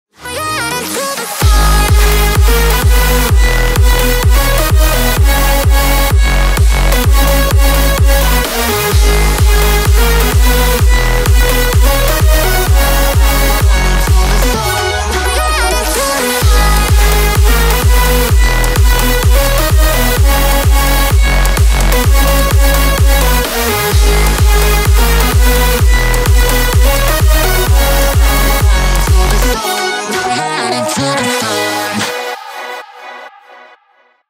• Качество: 320, Stereo
громкие
жесткие
мощные
заводные
Electronic
EDM
качающие
взрывные
энергичные
Big Room